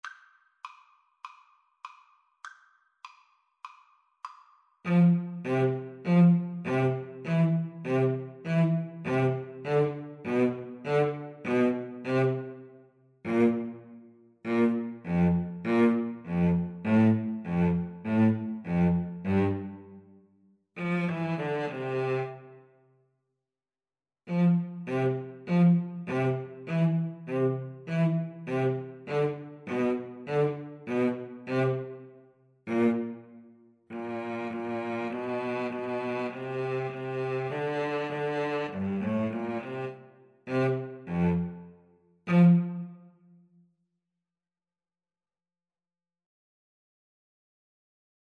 A light-hearted Ragtime-style piece.
4/4 (View more 4/4 Music)
Oboe-Cello Duet  (View more Easy Oboe-Cello Duet Music)
Jazz (View more Jazz Oboe-Cello Duet Music)